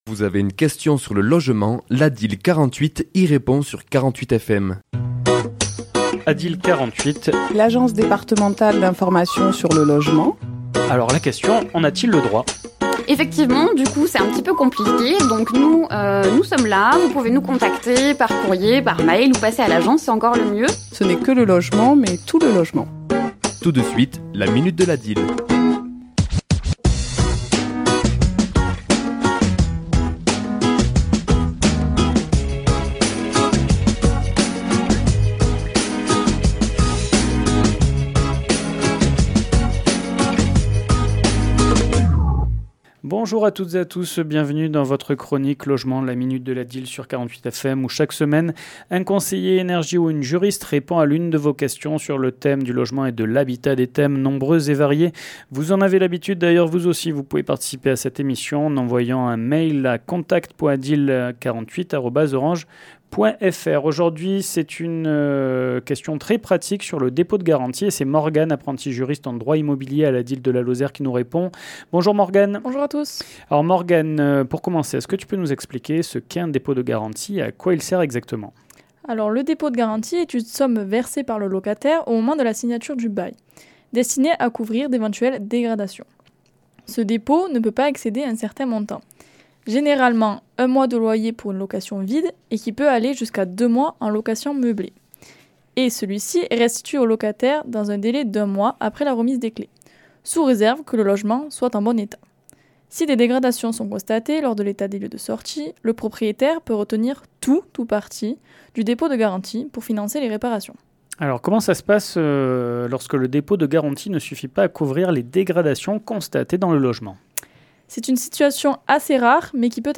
ChroniquesLa minute de l'ADIL
Chronique diffusée le mardi 24 décembre à 11h et 17h10